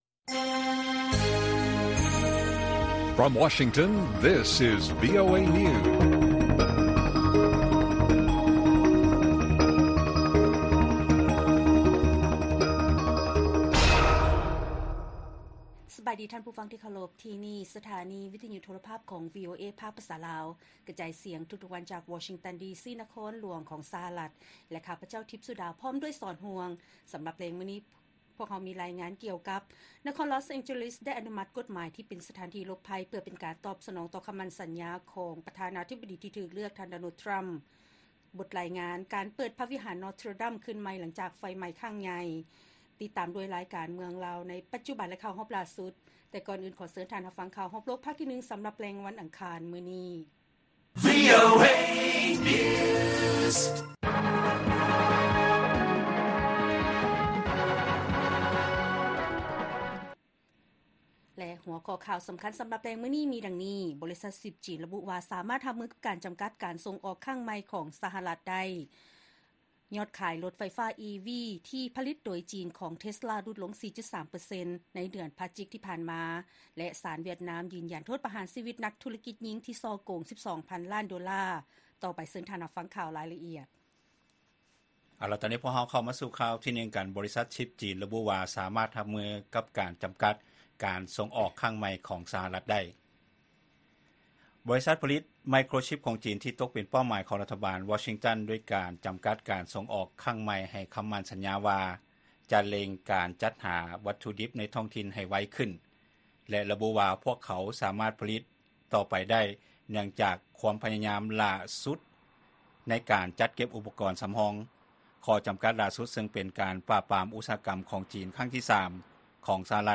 ລາຍການກະຈາຍສຽງຂອງວີໂອເອລາວ: ບໍລິສັດຊິບຈີນລະບຸວ່າ ສາມາດຮັບມືກັບການຈຳກັດການສົ່ງອອກຄັ້ງໃໝ່ຂອງສະຫະລັດໄດ້